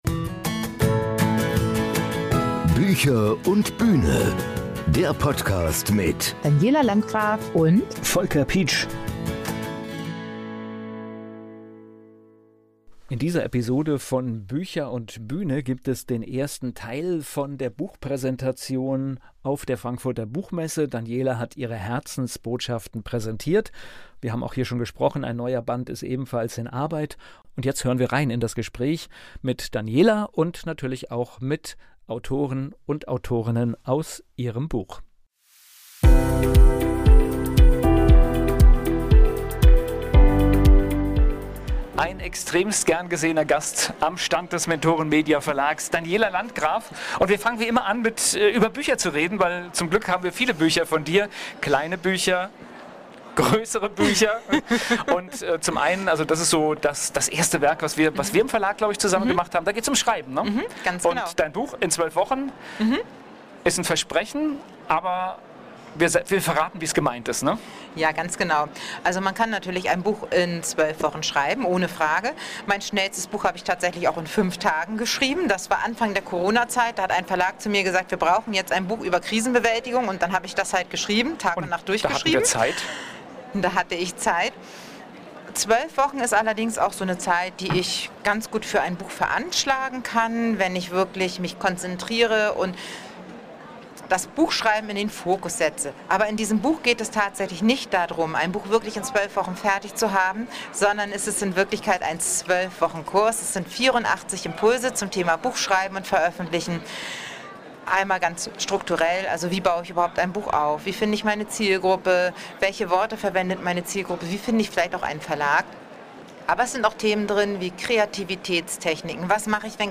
Es geht um Selbstwirksamkeit, Mut, die Macht der Gedanken und die große Frage, ob Gut und Böse wirklich so eindeutig sind, wie wir glauben. Diese Episode ist der Auftakt zu einem zweiteiligen Gespräch – persönlich, inspirierend und nah an der Realität von Schreibenden, Bühnenmenschen und all jenen, die ihre Geschichten in die Welt bringen wollen.